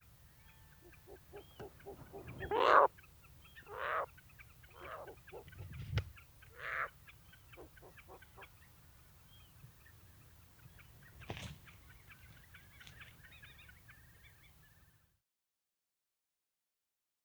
Every time this male hooted, his voice had a markedly gruff timbre. In this recording he hoots three times. The female goes out to meet him, giving a very short, slightly descending variant of her soliciting call.
CD2-82: Short-eared Owl Asio flammeus Finstown, Orkney, Scotland, 00:41, 7 June 2010. Gruff hooting of a male with short soliciting call of a female.
2-82-Short-eared-Owl-Gruff-hooting-of-male-with-short-soliciting-call-of-female.wav